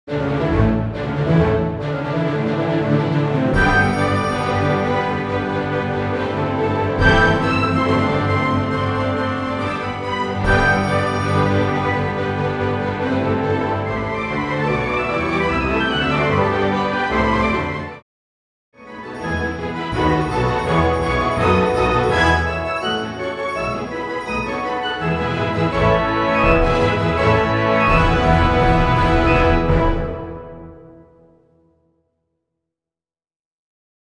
Full Orch accompaniment